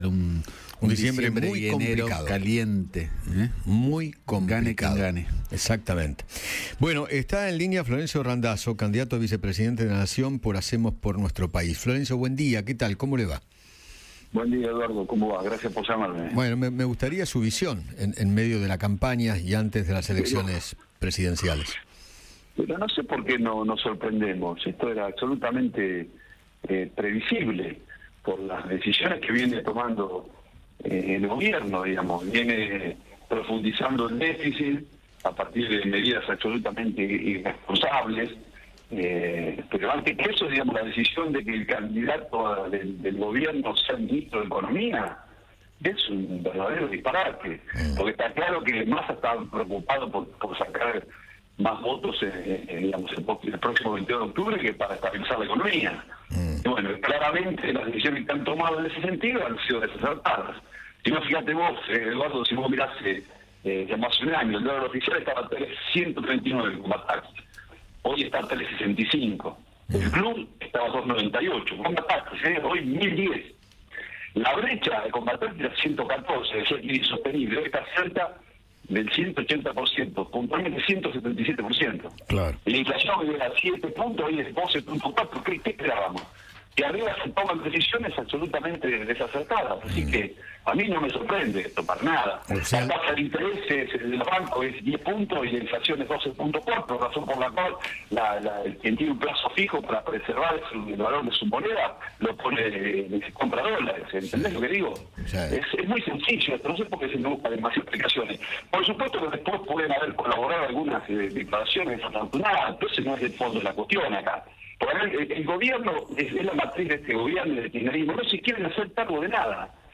Florencio Randazzo, candidato a vicepresidente de la Nación por Hacemos por Nuestro País, habló con Eduardo Feinmann sobre la actualidad política y económica del país.